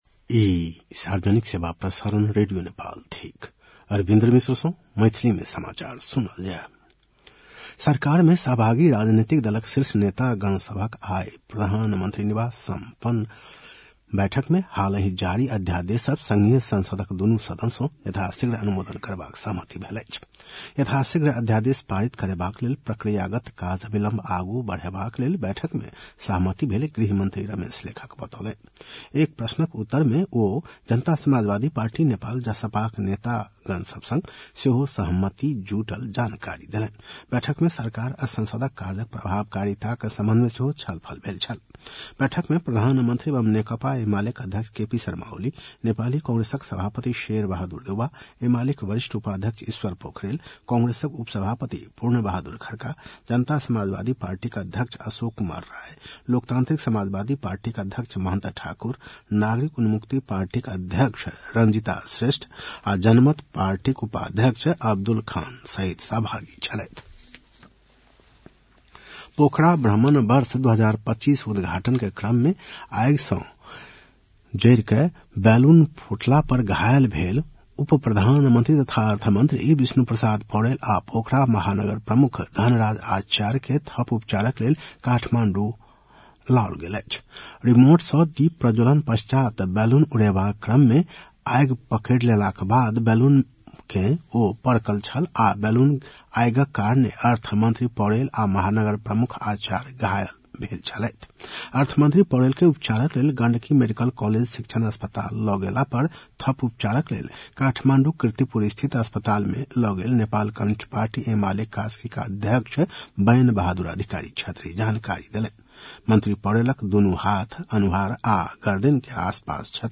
मैथिली भाषामा समाचार : ४ फागुन , २०८१